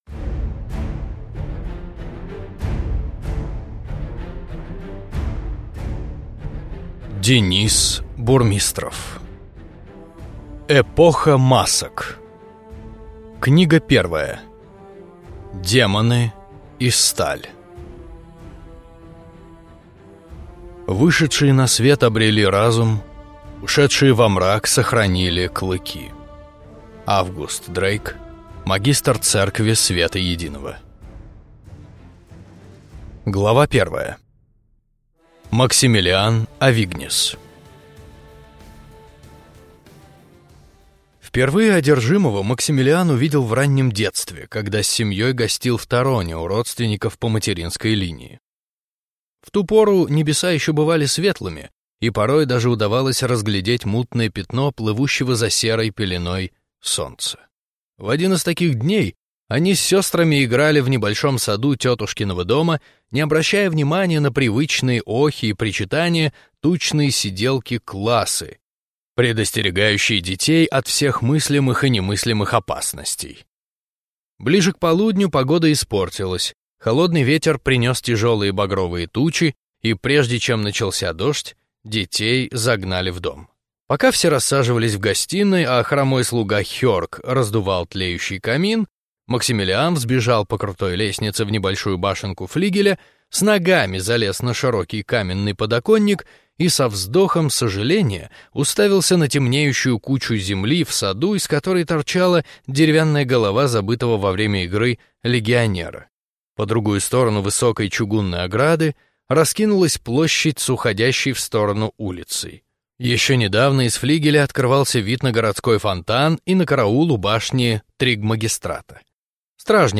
Аудиокнига Демоны и сталь | Библиотека аудиокниг
Прослушать и бесплатно скачать фрагмент аудиокниги